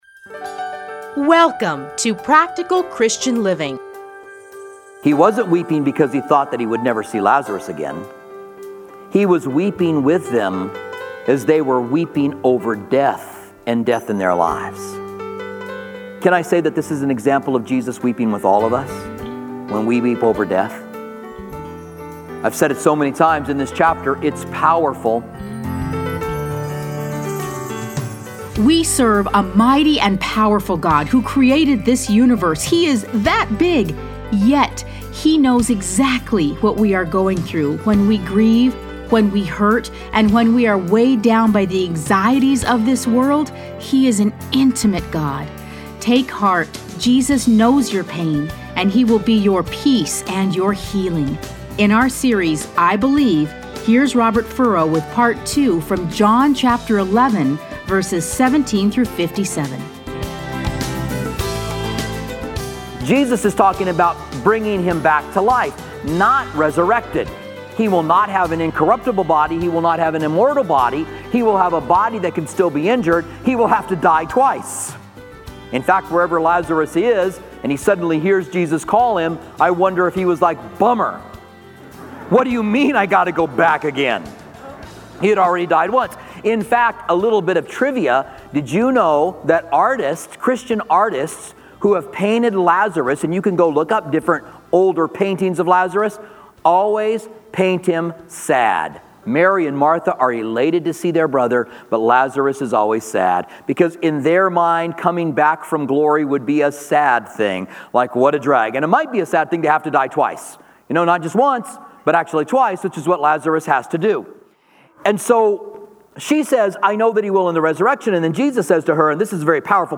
Listen to a teaching from John 11:17-57.